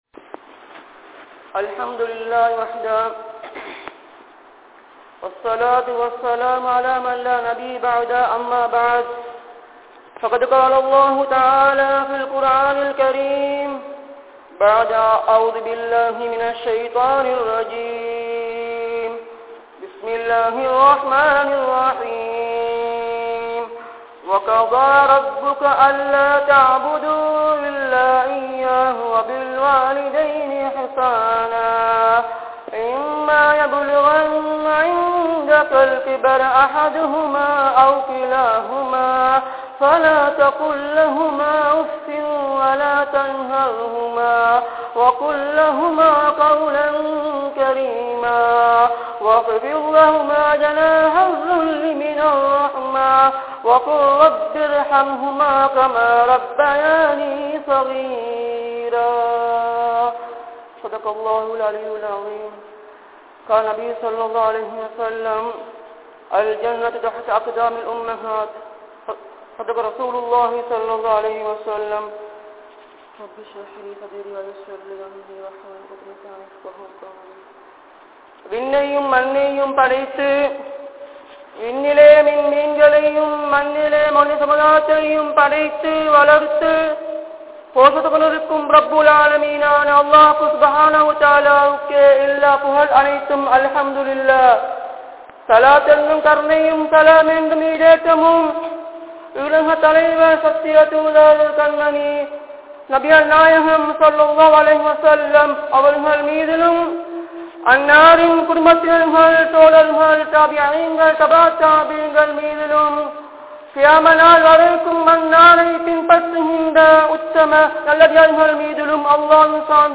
Pillaihalin Kadamaihal (பிள்ளைகளின் கடமைகள்) | Audio Bayans | All Ceylon Muslim Youth Community | Addalaichenai
Karawira Jumua Masjidh